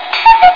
CUCKOO.mp3